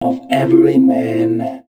039 male.wav